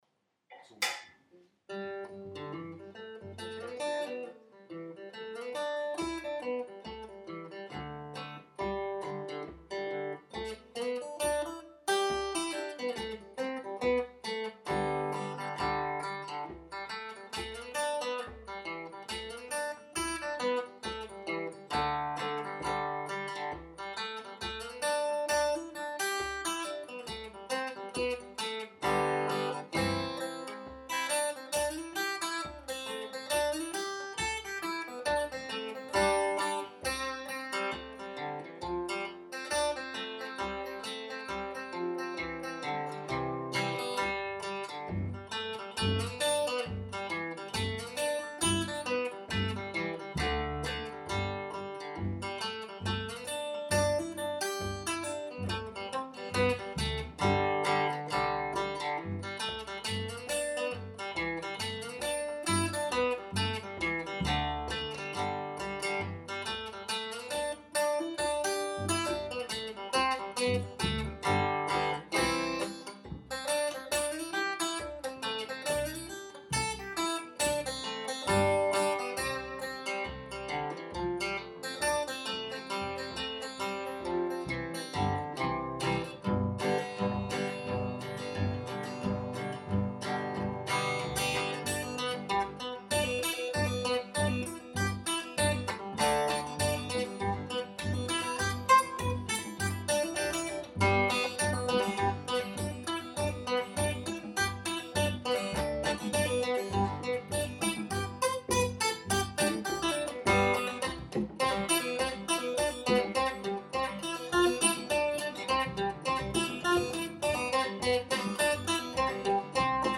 Genre: Folk.